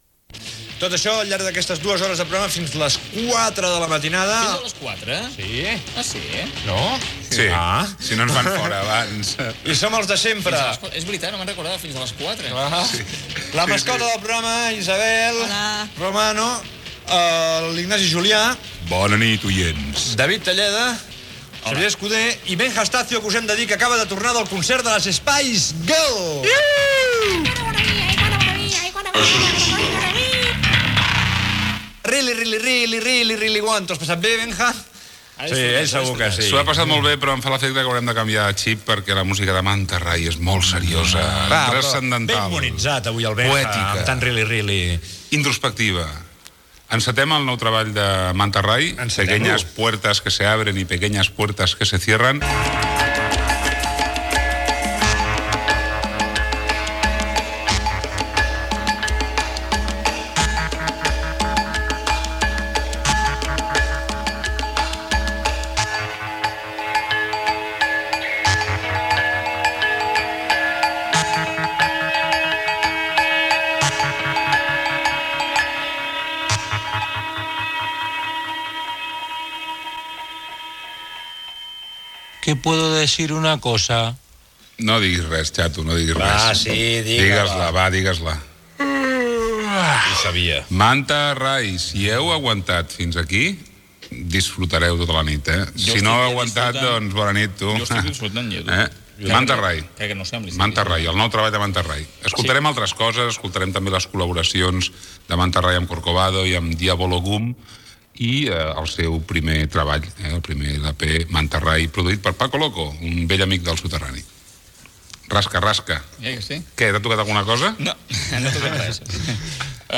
Presentació, equip, tema musical, agenda de concerts i tema musical
Musical